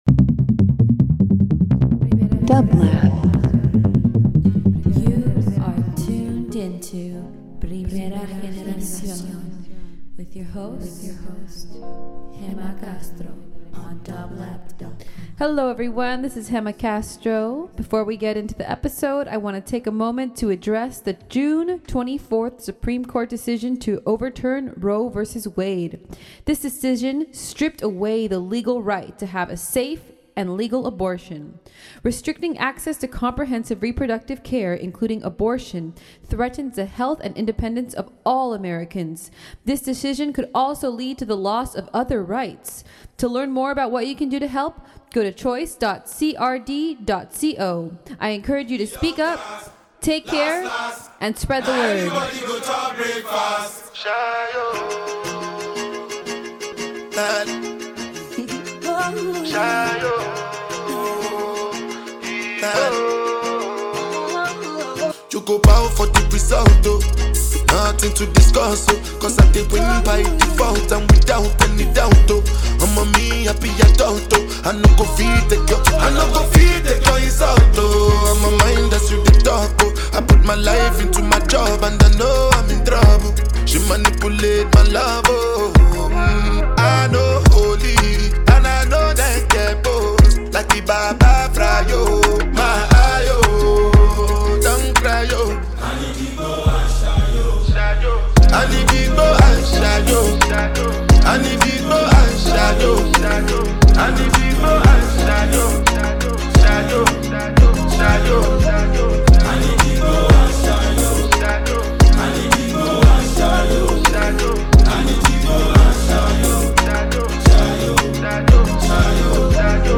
Ambient Electronic Reggaeton Talk Show